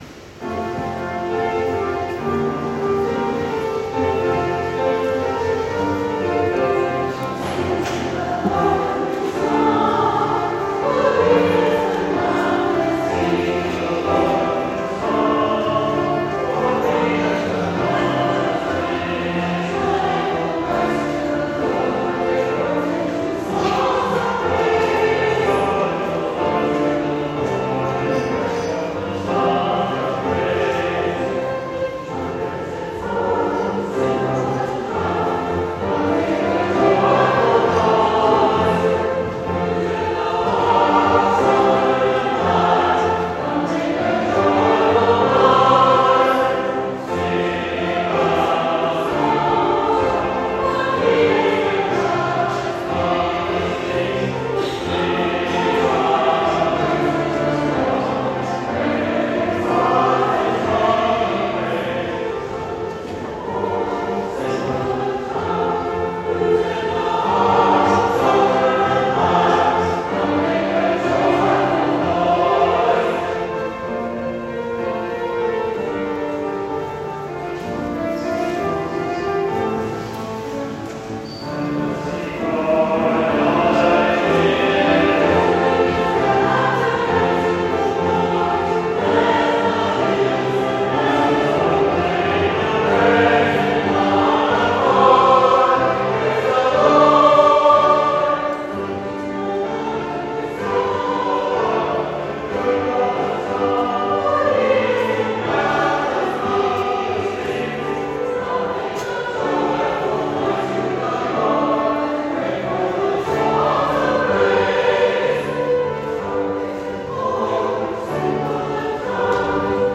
St. Jude of the Lake Parish Choir